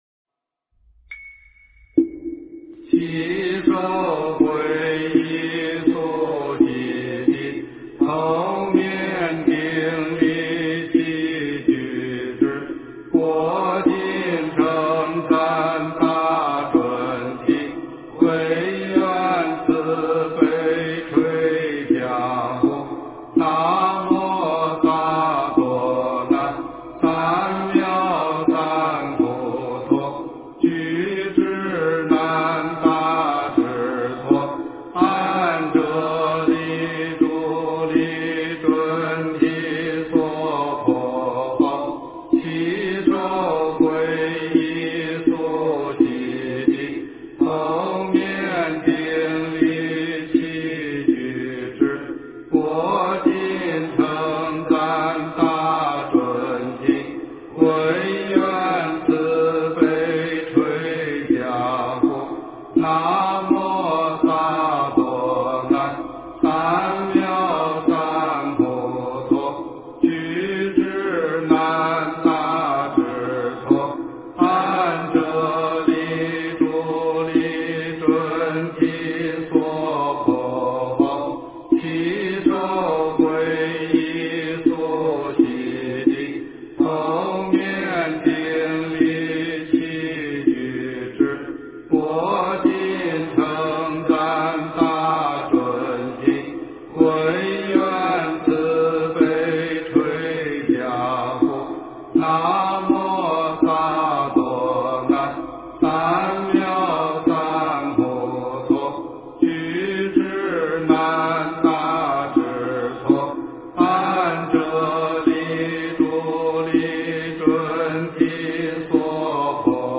经忏
佛音 经忏 佛教音乐 返回列表 上一篇： 文殊菩萨盛名咒-唱颂版--未知 下一篇： 大悲咒-功课--寺院 相关文章 《妙法莲华经》如来神力品第二十一--佚名 《妙法莲华经》如来神力品第二十一--佚名...